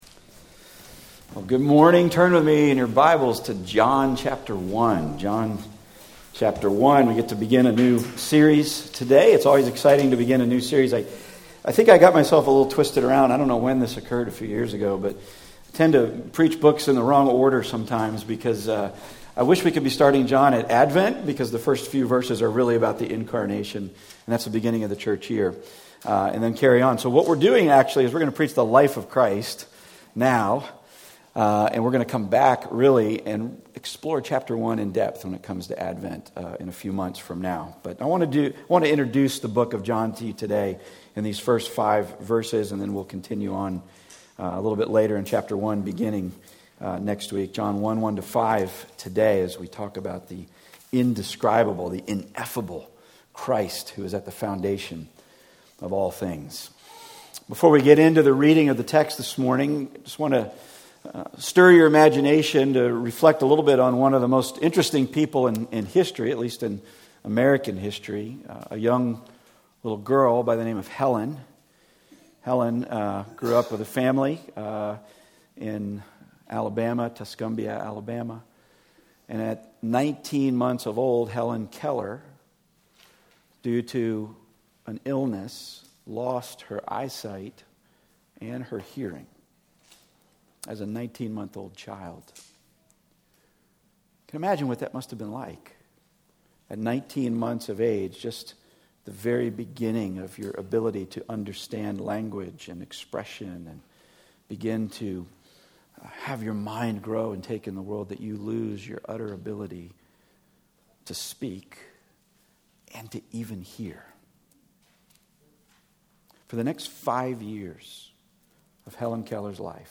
Passage: John 1:1-5 Service Type: Weekly Sunday